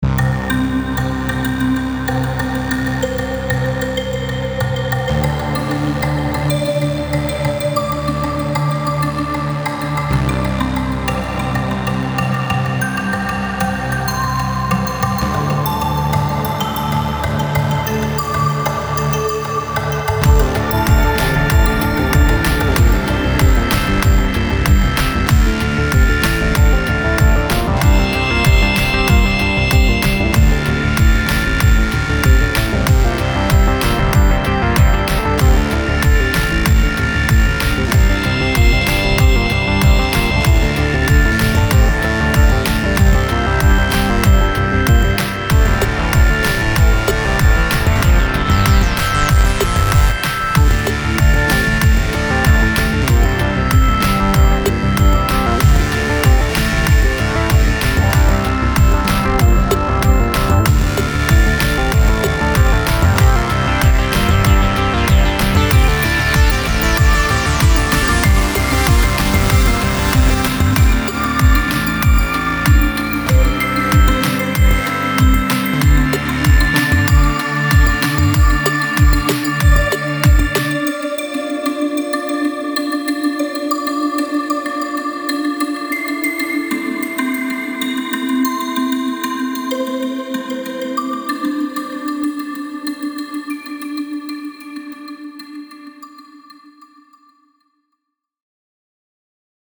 Here's a heavy synth track I put together while I was watching election returns come in: